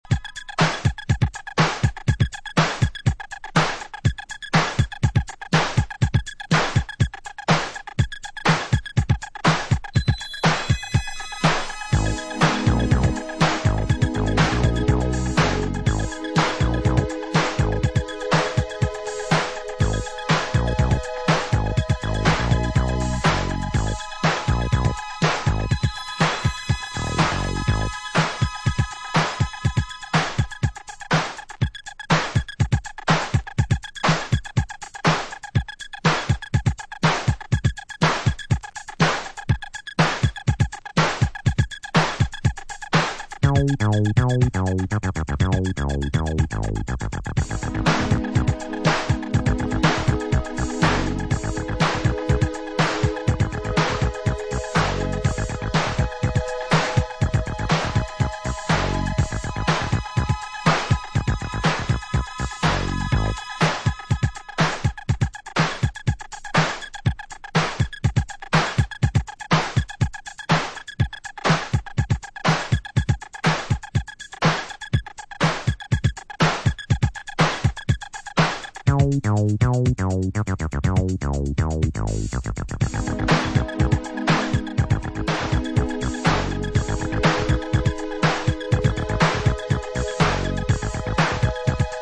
Cool electro stomper from way back, must have!